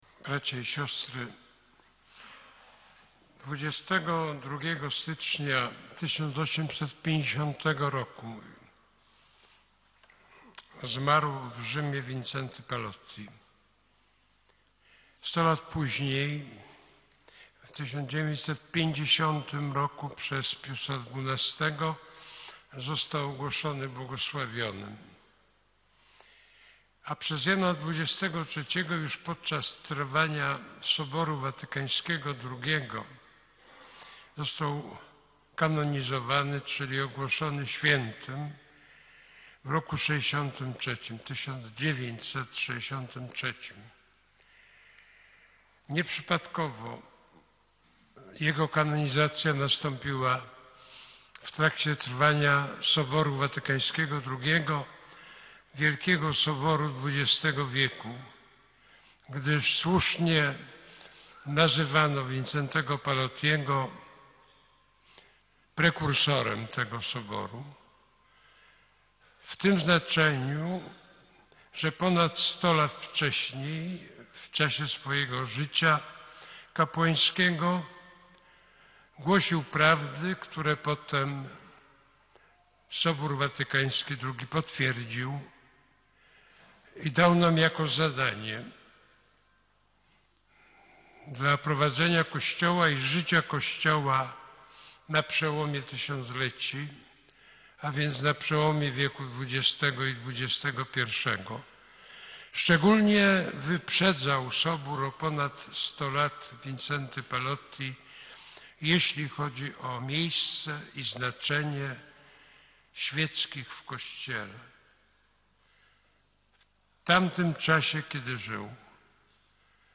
homilia-Nycz.mp3